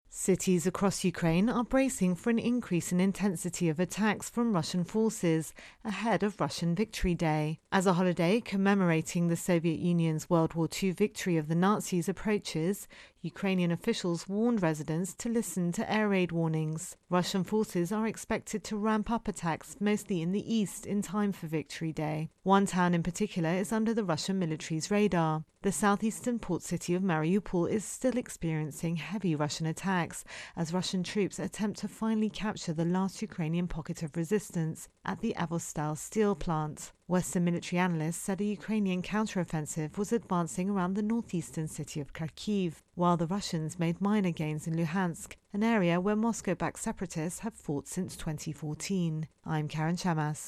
Intro and voicer on 'Russia Ukraine War'.